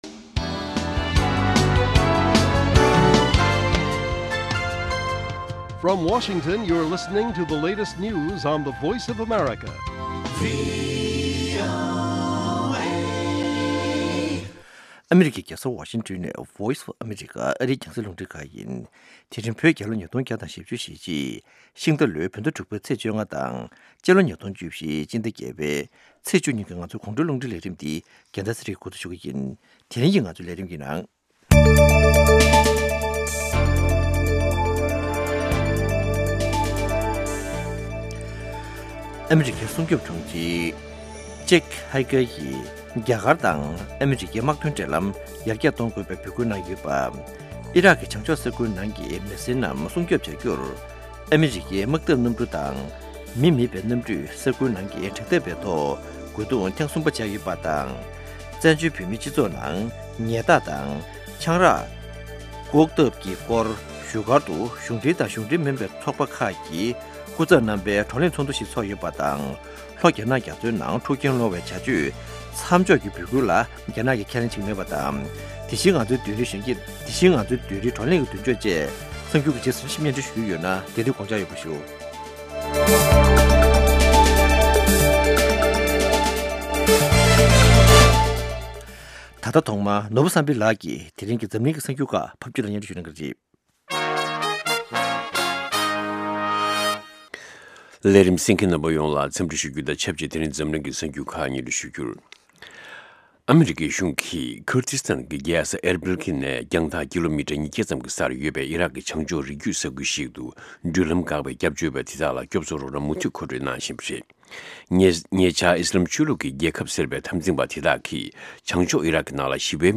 དགོང་དྲོའི་གསར་འགྱུར།